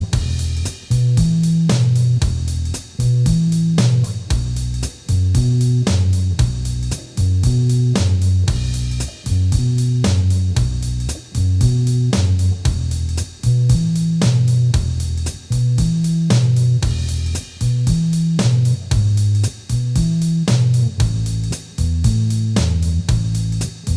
Minus Guitars Rock 2:45 Buy £1.50